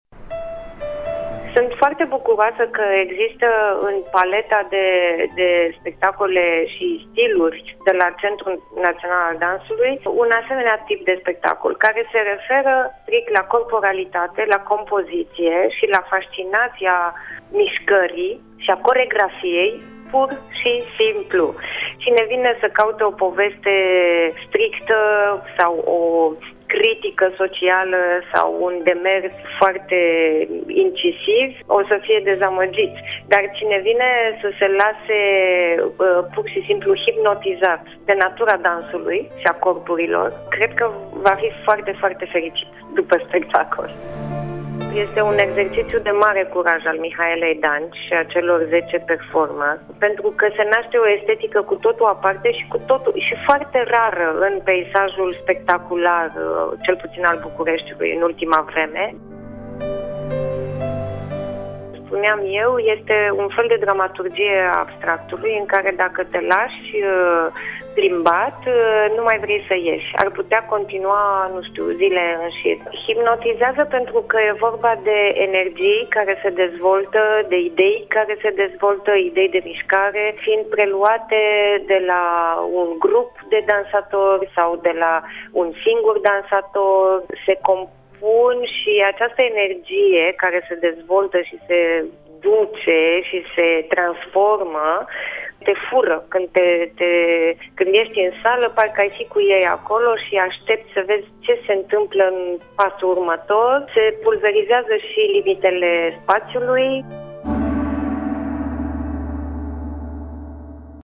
cu un fragment dintr-un interviu